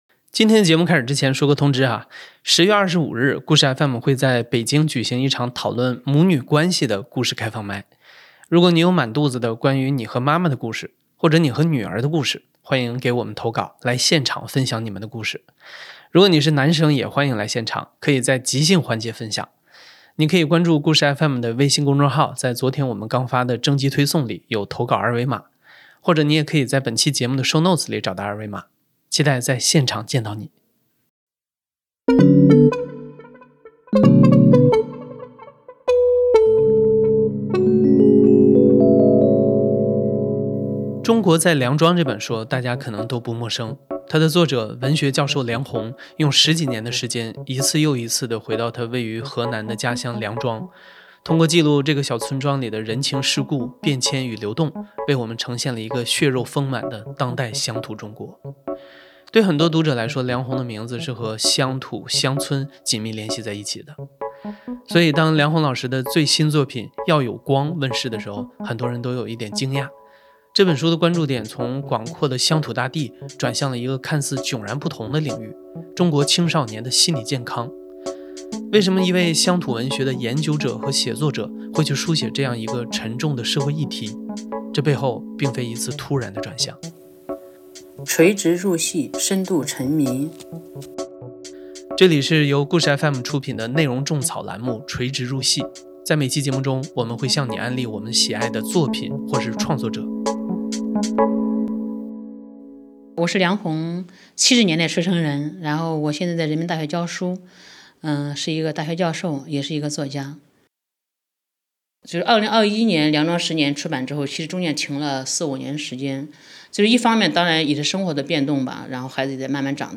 讲述者 | 梁鸿 主播
故事FM 是一档亲历者自述的声音节目。